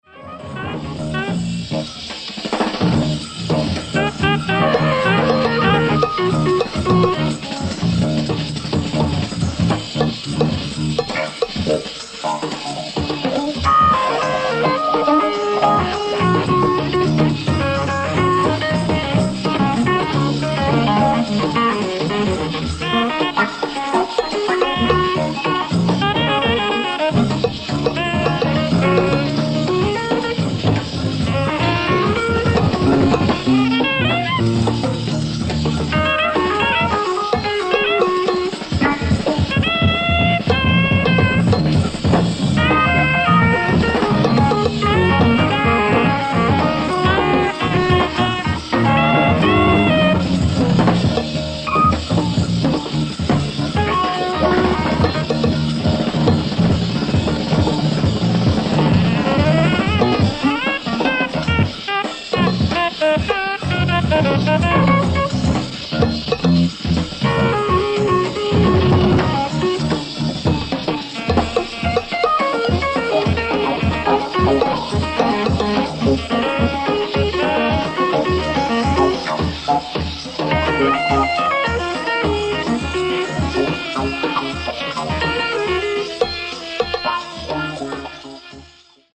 STEREO SOUNDBOARD RECORDING